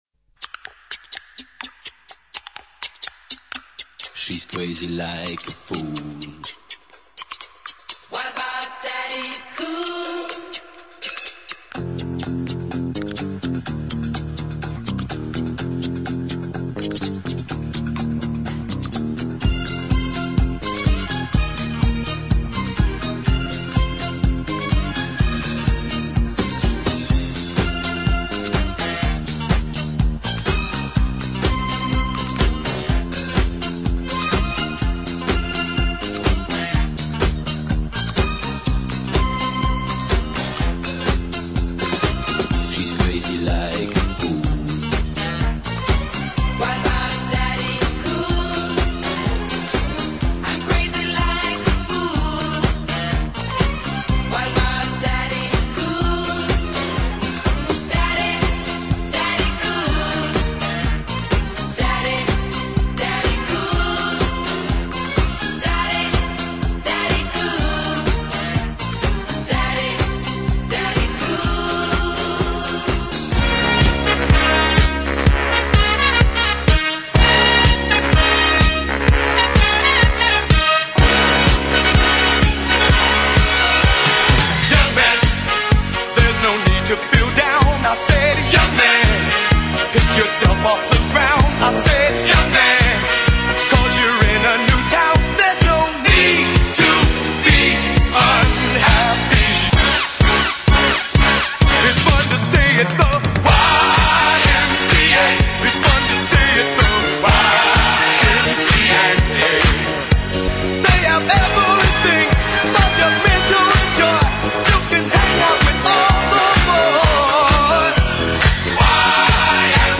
• Latin/Salsa/Reggae